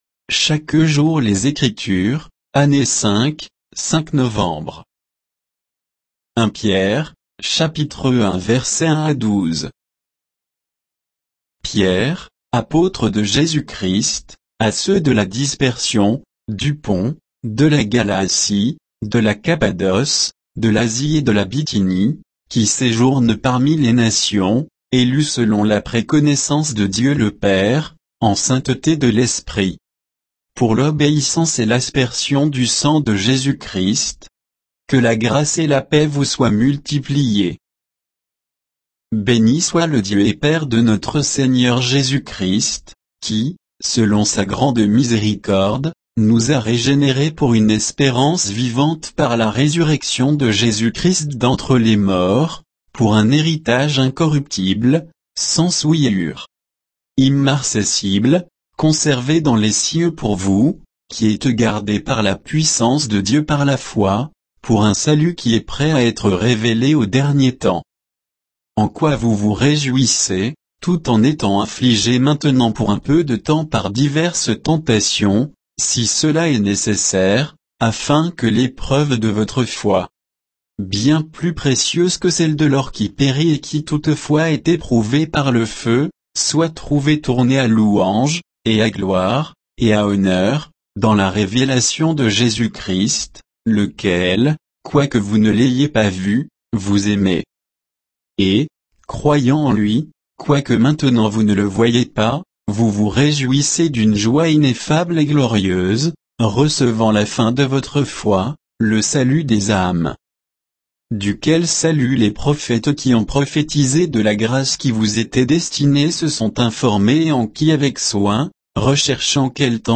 Méditation quoditienne de Chaque jour les Écritures sur 1 Pierre 1